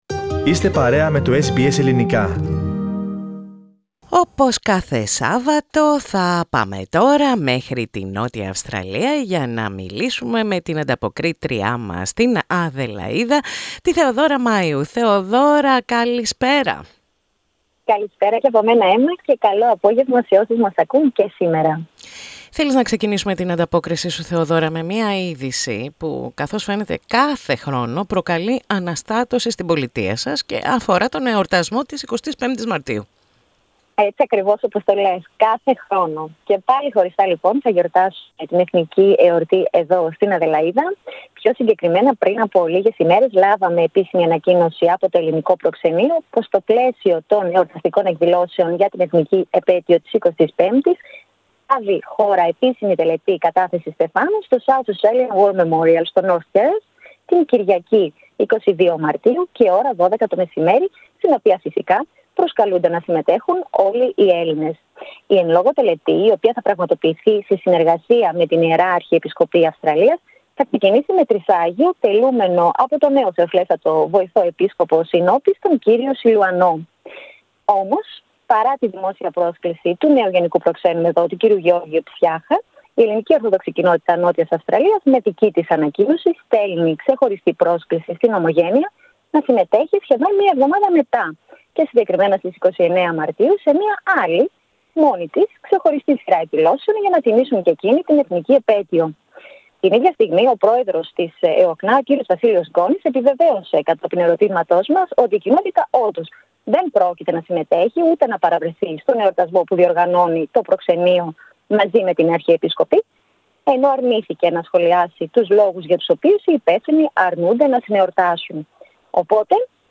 Ακούστε την ανταπόκριση απο την Νότια Αυστραλία πατώντας play στο podcast που συνοδεύει την αρχική φωτογραφία.